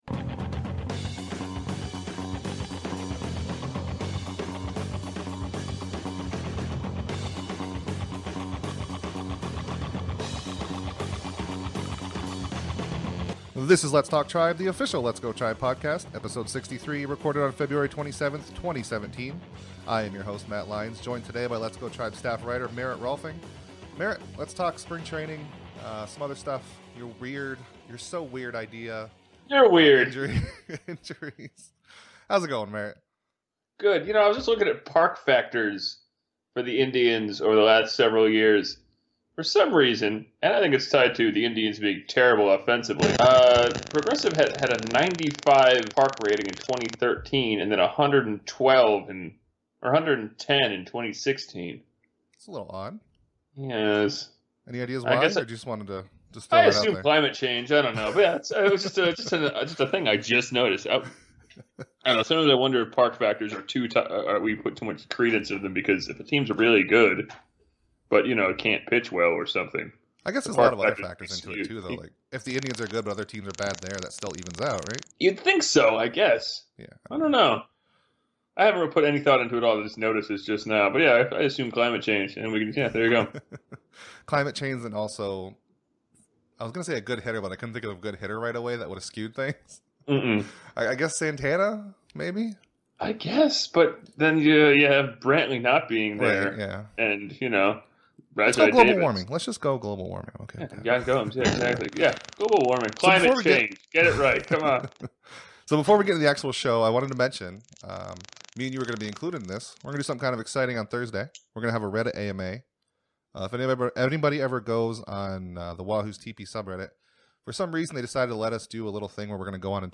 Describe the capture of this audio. Sorry about the annoying helicopter sound that appears frequently at the beginning, it goes away and I'm not sure what caused it. But hey, everything is recorded on a shiny new mic!